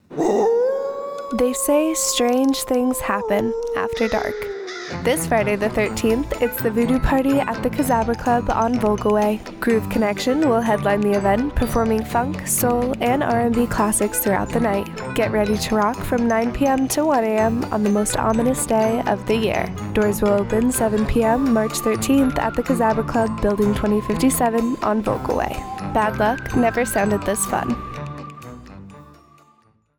This is a 30-second radio spot advertising a voodoo party at the Vogelweh Kazabra Club that will air on AFN Kaiserslautern from Jan. 30, 2026, to March 13, 2026, in Kaiserslautern, Germany.
VogelwehFriday the 13thRadio SpotKazabra Club